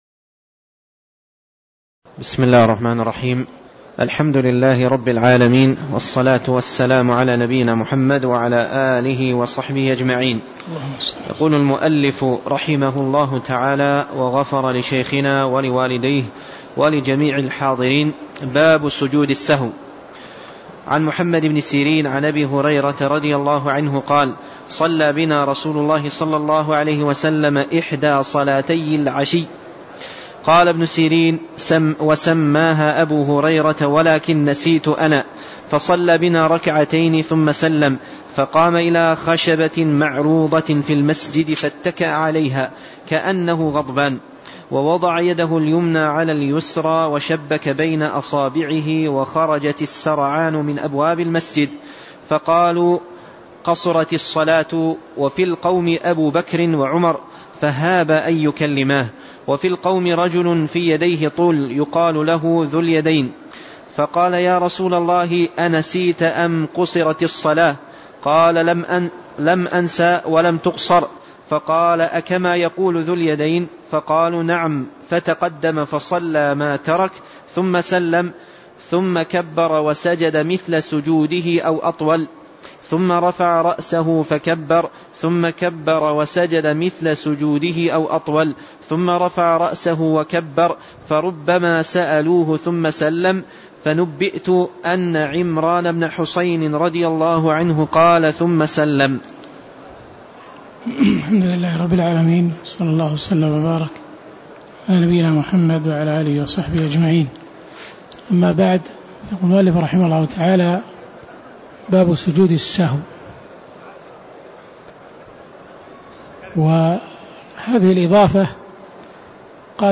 تاريخ النشر ٣ ربيع الأول ١٤٣٧ هـ المكان: المسجد النبوي الشيخ